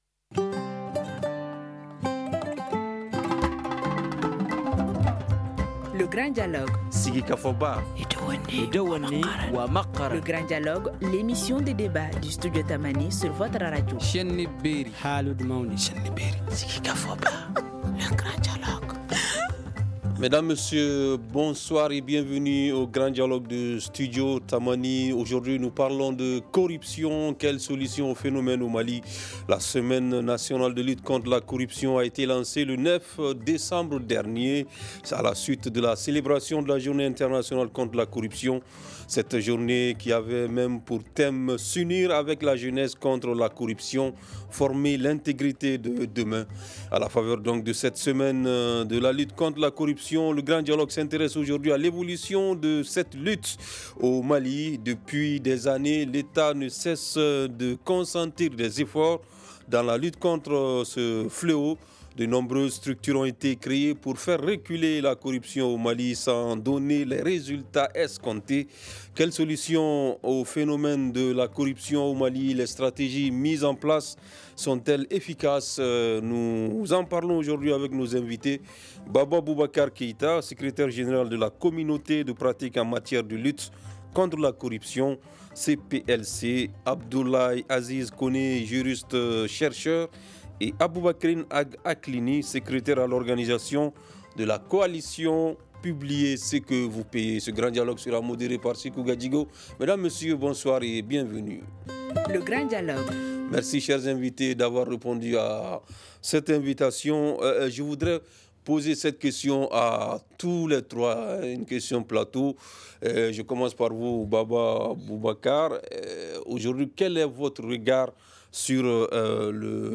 Nous en parlons aujourd’hui avec nos invités :